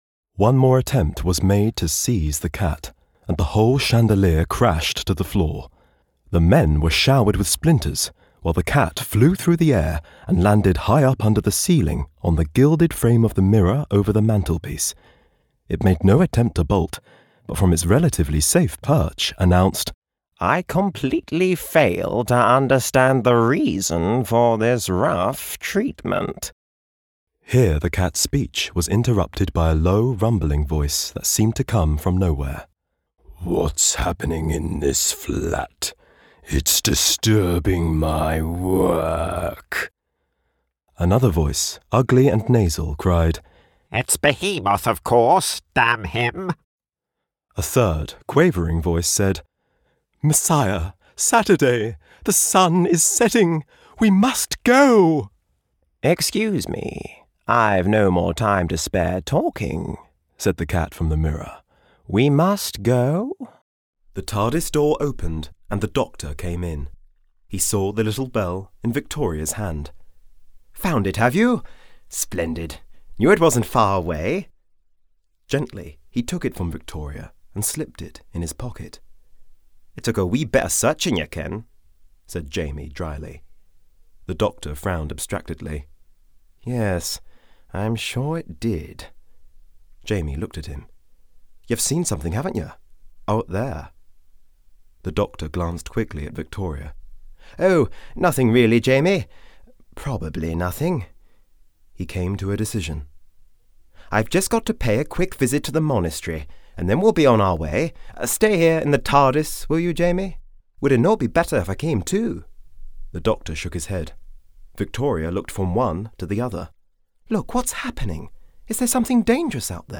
2. Audiobook
HOME STUDIO
RP
African, American, Estuary, London, RP, Russian